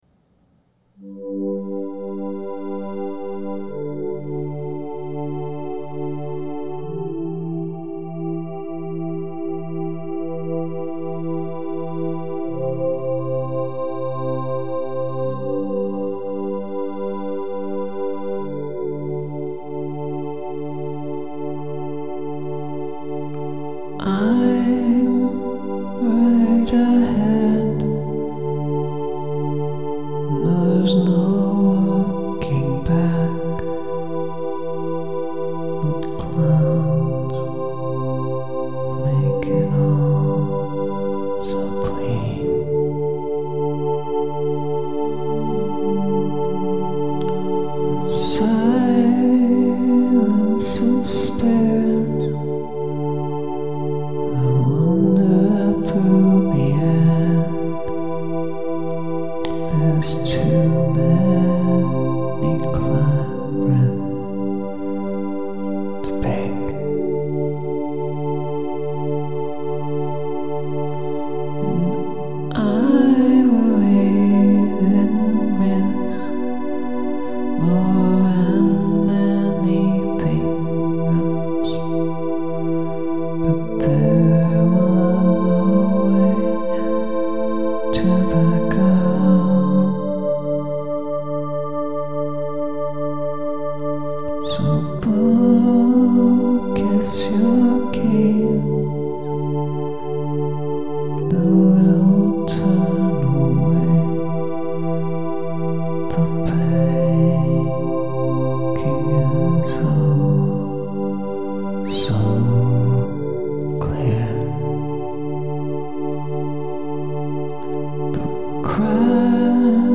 I didn't do it to a click, so it is even more sparse than the last one. Just a Brian Eno-ish pad and vocal which I did at the same time, with an overdubbed guitar at the end.
It is pretty, but I cannot understand the lyrics. :(